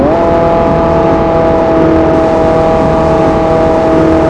focussvt_revdown.wav